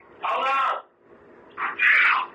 EVP-2___